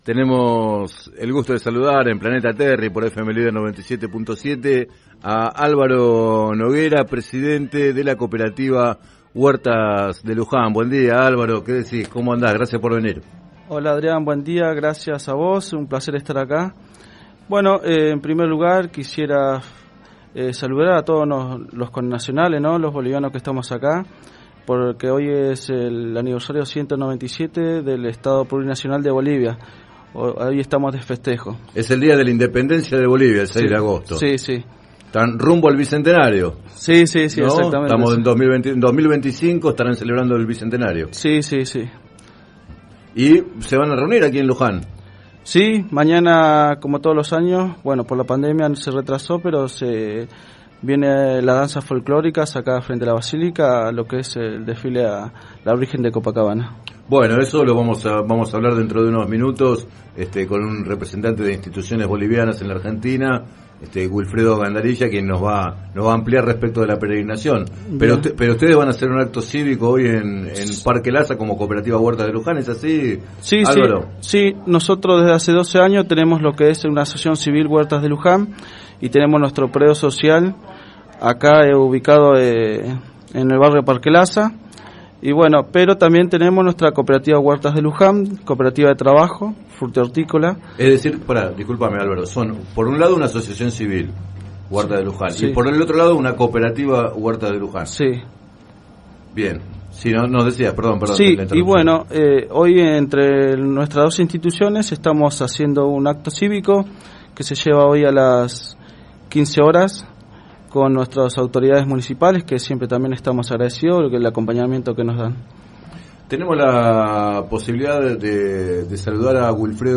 Entrevistados en el programa Planeta Terri de FM Líder 97.7